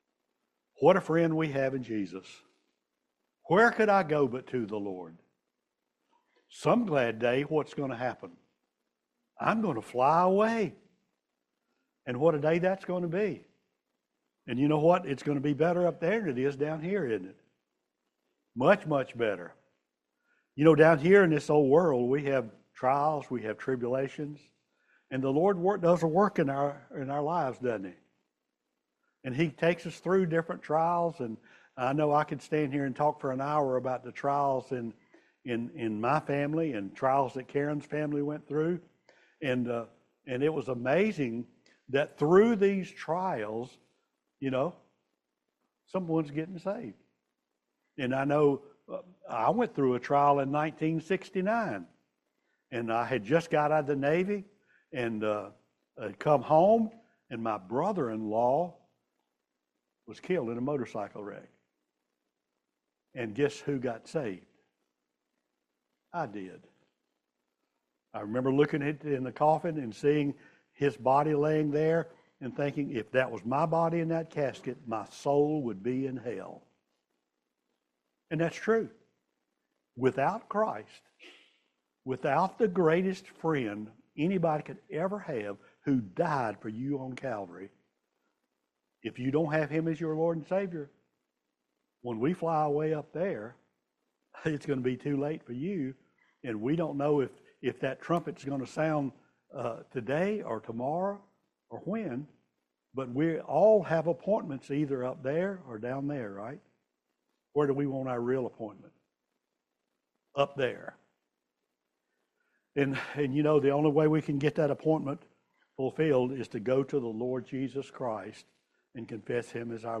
Psalm 73 Service Type: Family Bible Hour Look away from the ungodly and look to God.